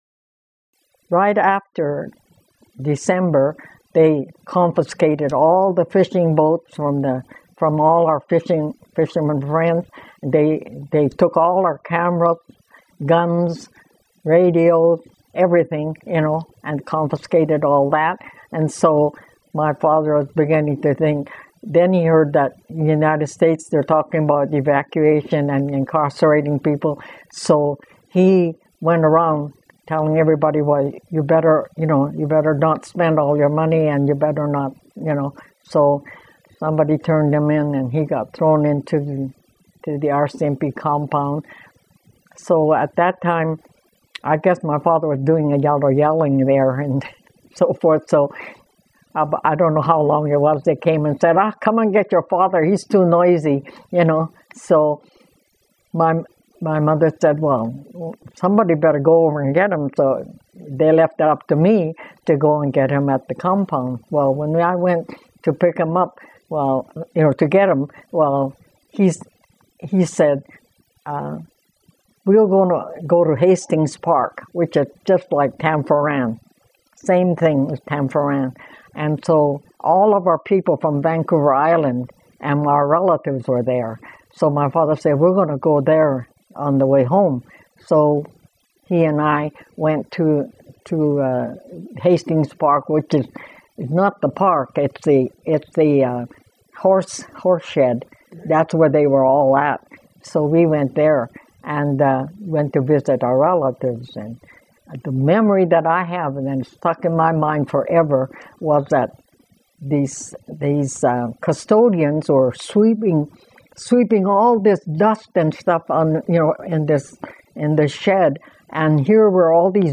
This was one of the longest interviews I've conducted so far.